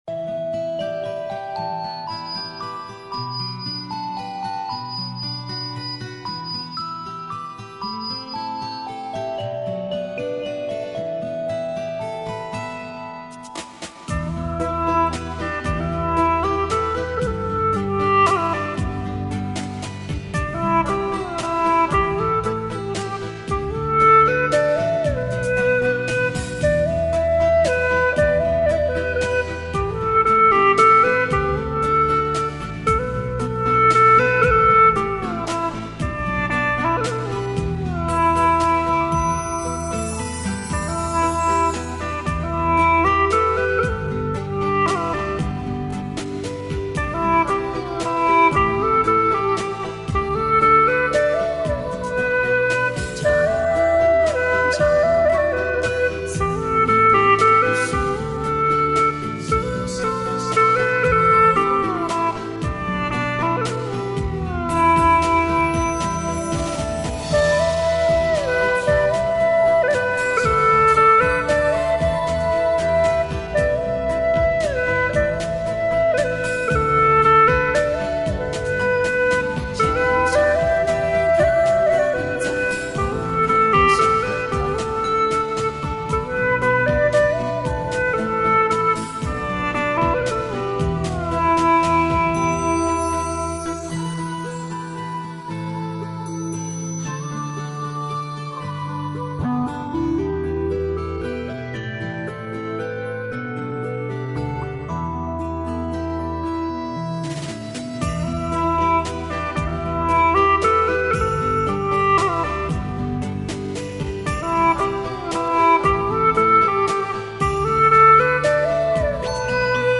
调式 : G 曲类 : 民族